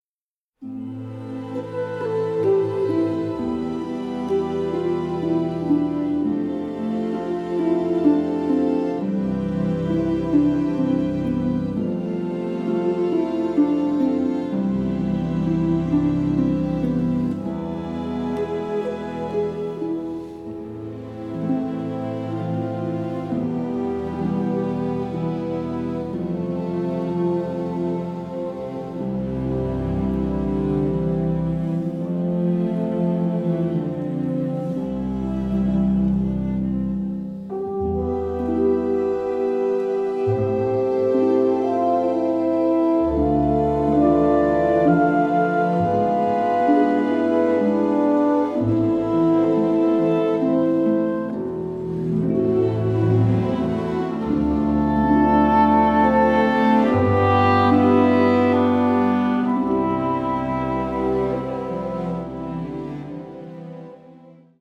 like a romantic and nostalgic musical journey.